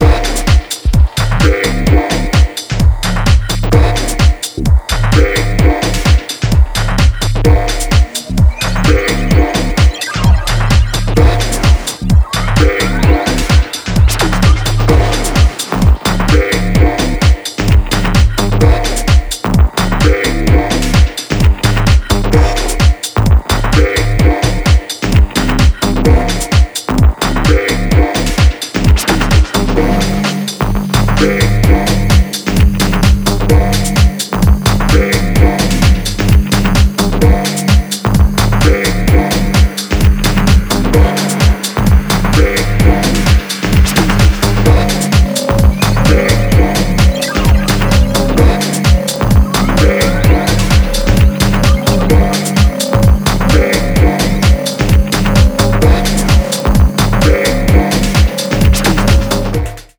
インダストリアルに燻んだ音像とサイファイなシンセコードの対比が物凄い